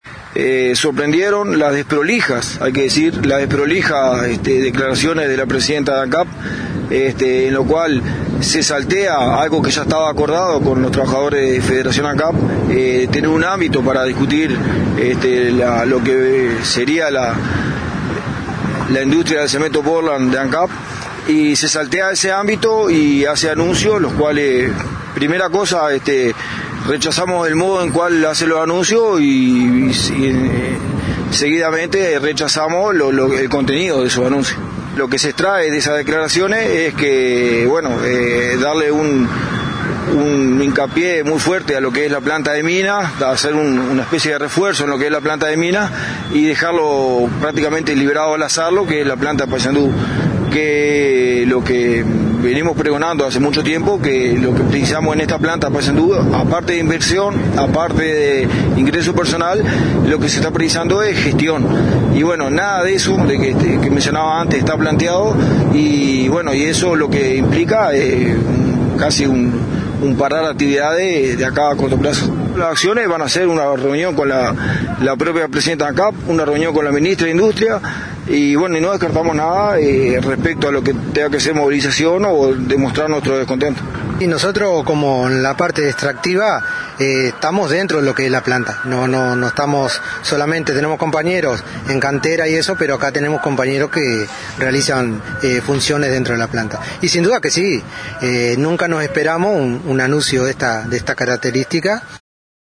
NOTA-ASAMBLEA-FANCAP-EN-PLANTA-PAYSANDU.mp3